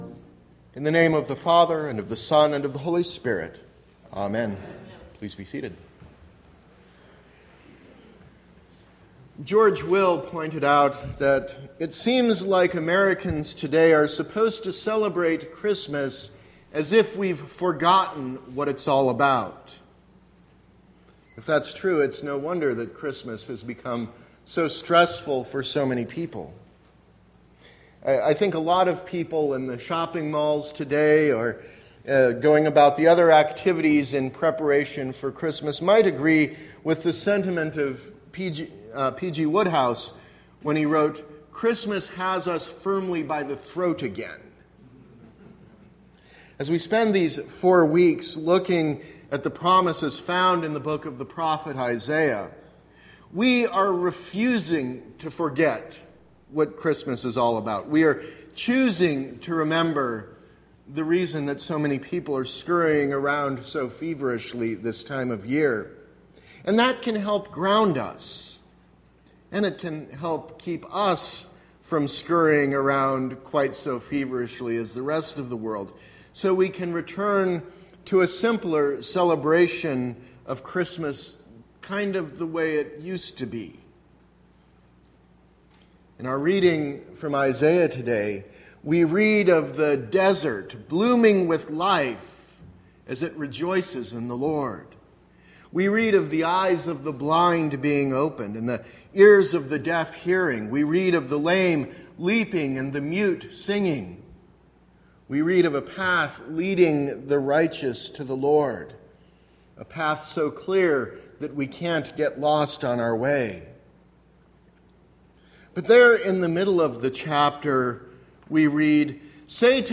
Sermon – December 11, 2016 – Advent Episcopal Church